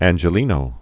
(ănjə-lēnō)